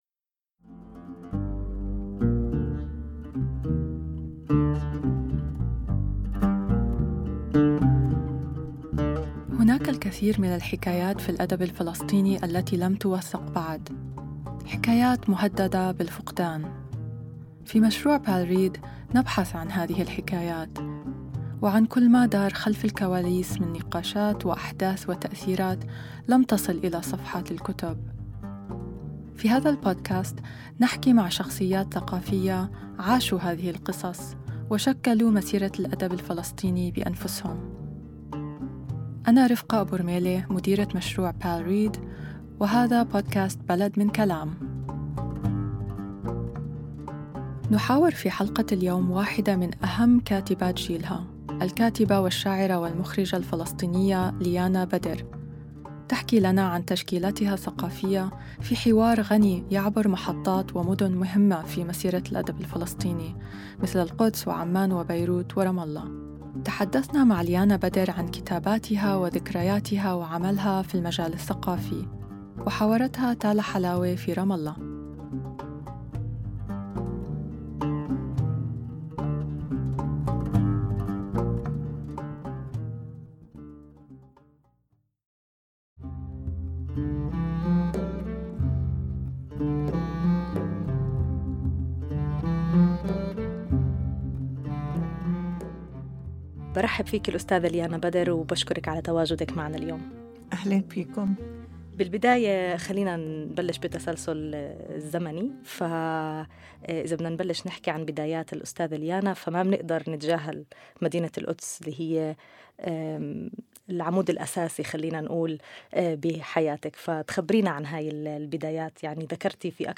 في هذه الحلقة حوار مع الشاعر غسان زقطان حول مسيرته الشعرية وكل الذين أسهموا في دفعوا خلال هذه المسيرة، والمجلات المختلفة التي عمل بها مثل الحرية والبيادر.